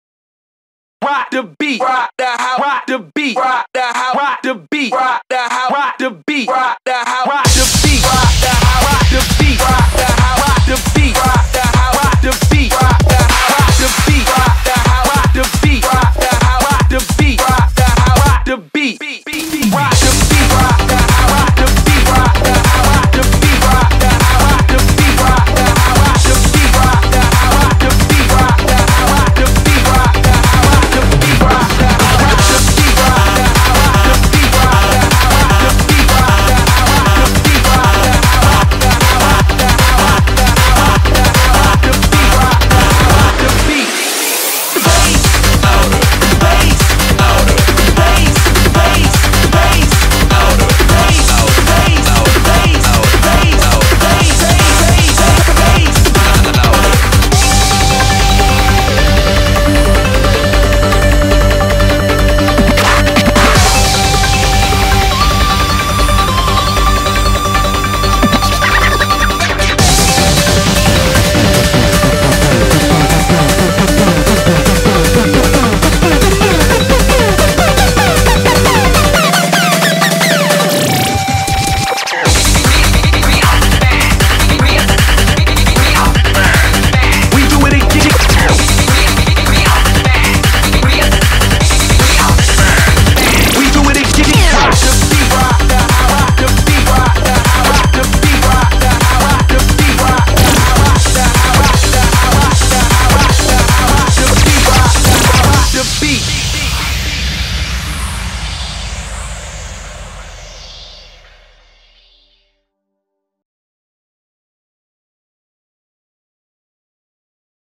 BPM154
Comments[TECHNO]